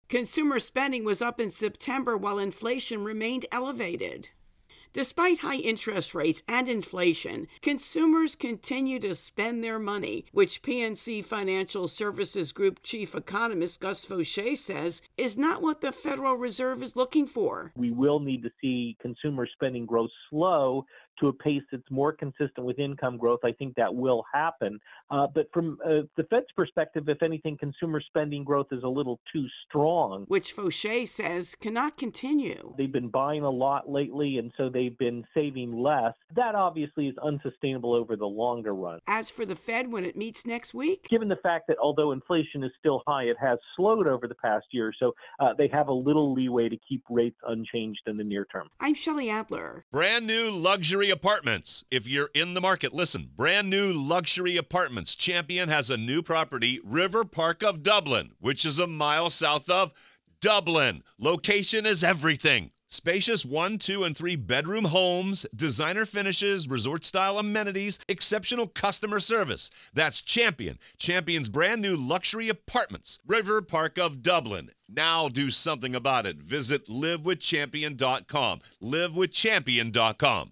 reports on consumer spending.